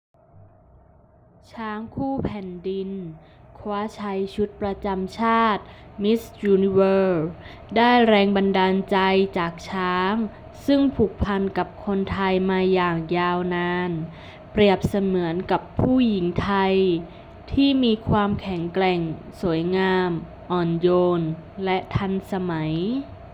★実際にニュースを読み上げたものはこちら。